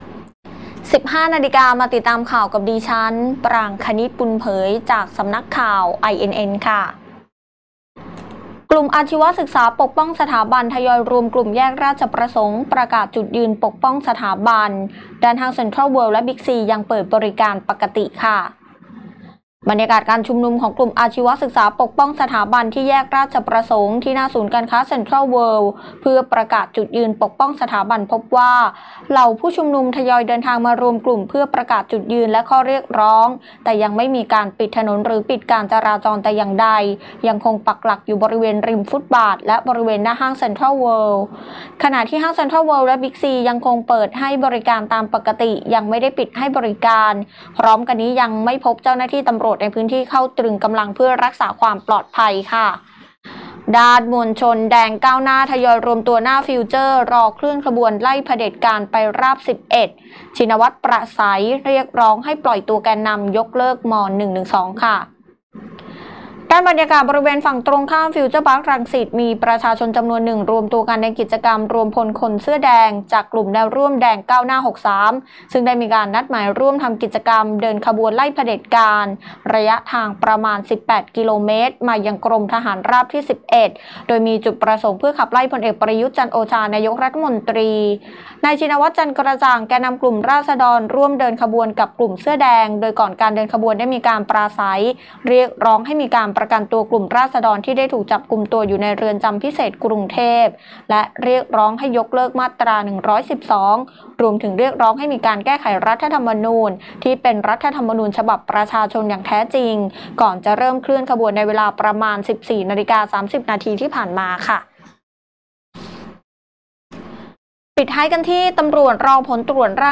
ข่าวต้นชั่วโมง 15.00 น.